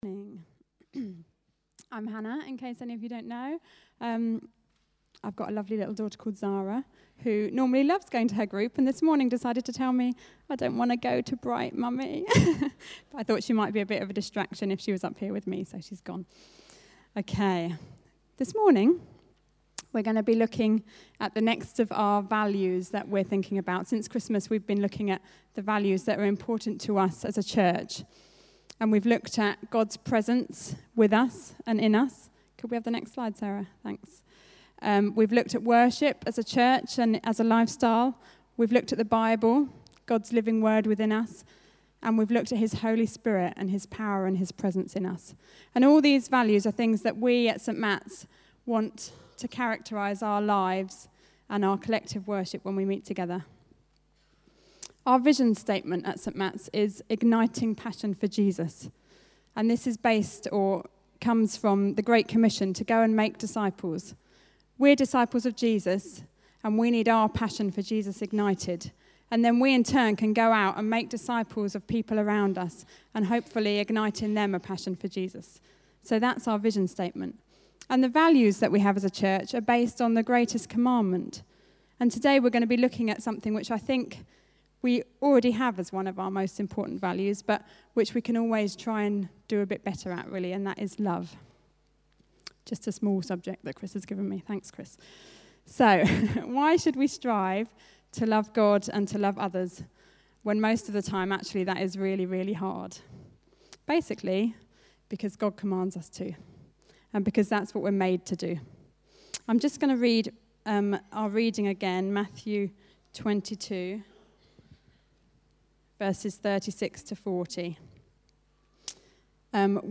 Bible Text: Matthew 22:36-40 | Preacher